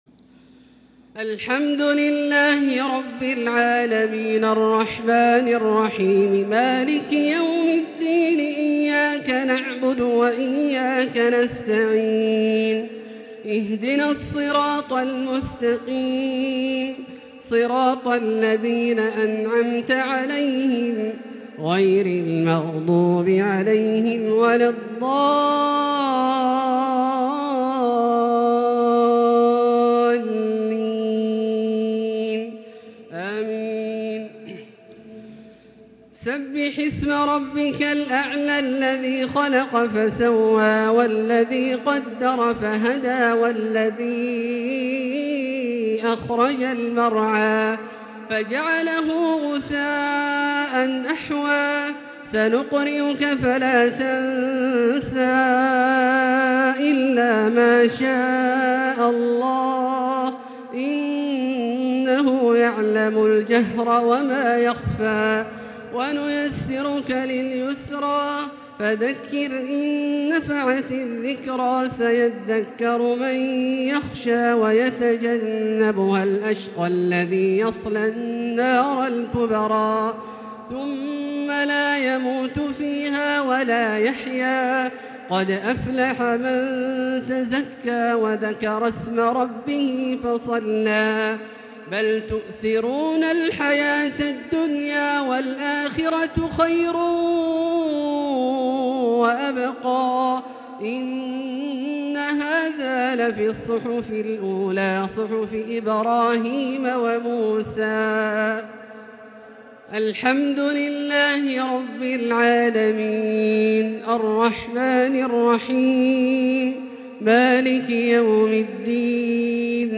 صلاة الجمعة 6-12_1442 سورتي الأعلى و الغاشية > ١٤٤٢ هـ > الفروض - تلاوات عبدالله الجهني